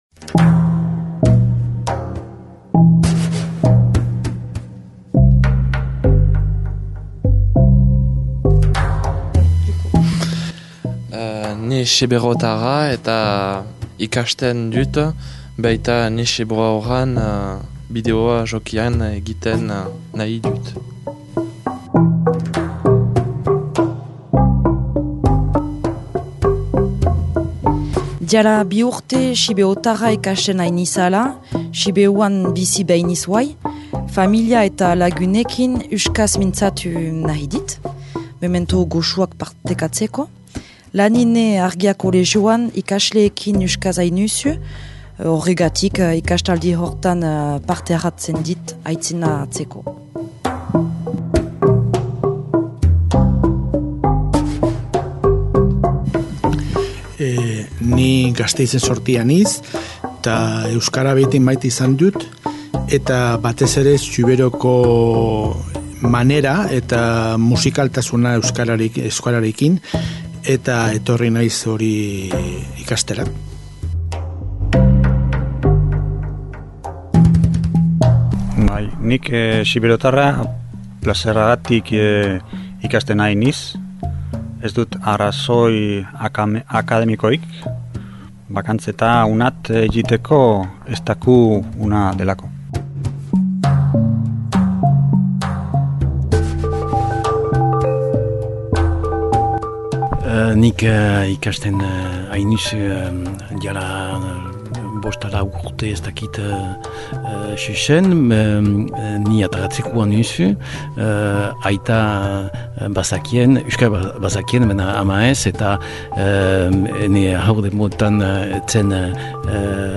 Irrati tailerran zentako Xiberotarra ikasi nahi düen galtatü deiegü !!
Ikastaldiko ikasleak :